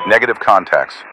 Added .ogg files for new radio messages
Radio-pilotWingmanReportContactsNoJoy1.ogg